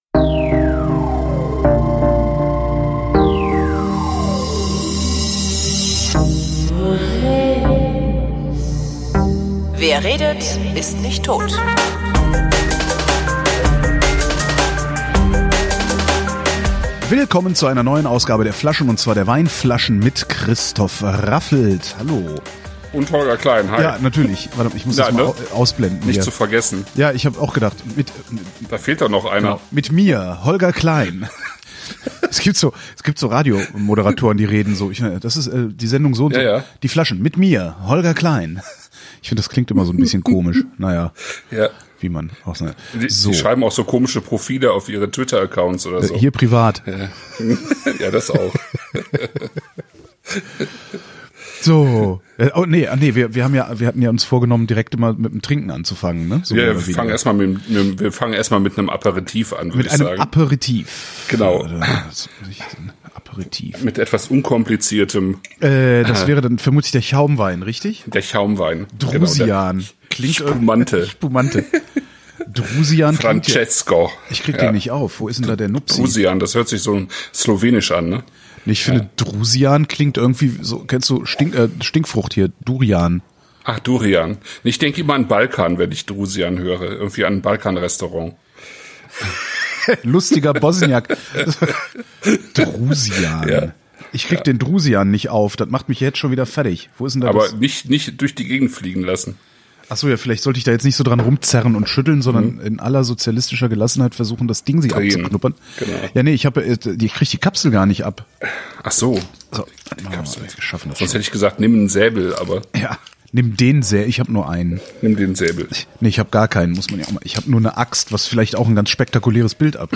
Wir trinken Drusian Francesco, Prosecco de Valdobbiadene Spumante »Extra Dry«, Corte Gardoni di Gianni Piccoli, Bianco di Custoza »Corte Gardoni« und Ca la Bionda, Valpolicella Superiore »Casal Vegri« und reden – unter anderem – über diese Weine, den Bib Gourmand, Fritten und Shakshuka.